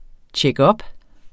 Udtale [ tjεgˈʌb ]